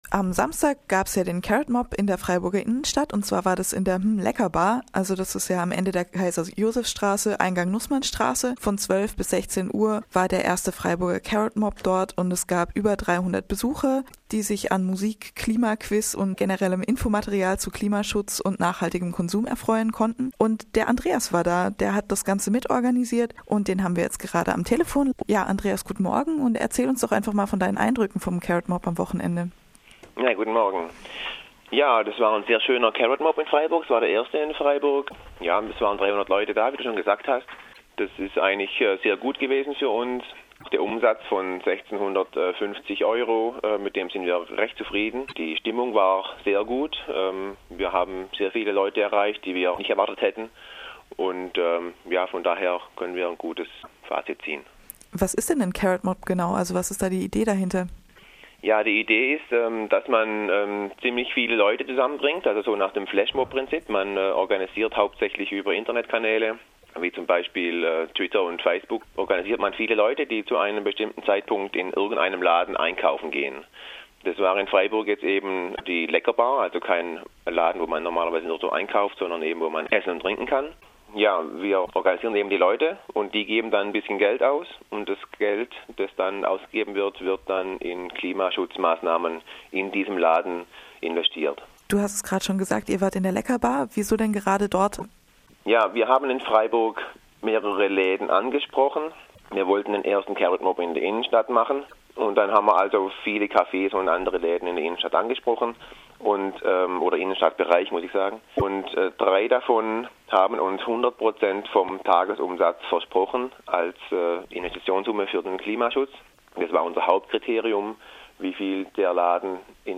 Interview zum ersten Carrotmob in Freiburg